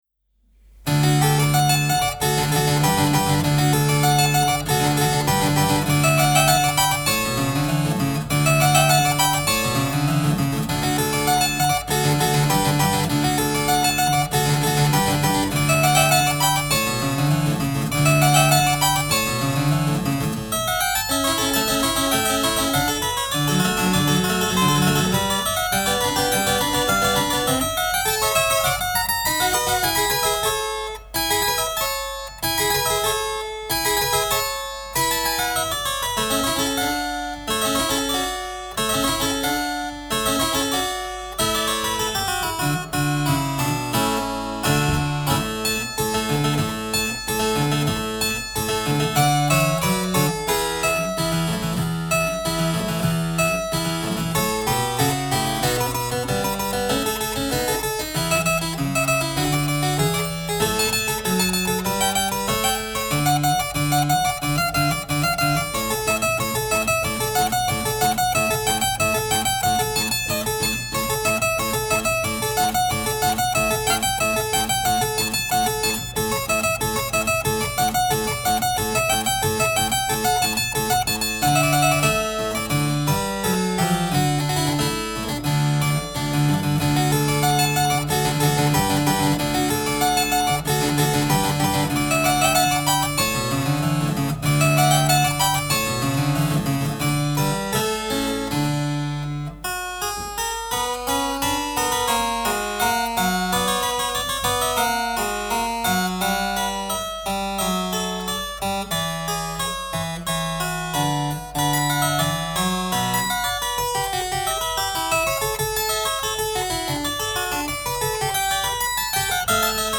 clavecinista
1977-Toccata.-Francisco-de-Ibarzabal.mp3